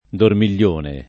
dormiglione [ dormil’l’ 1 ne ]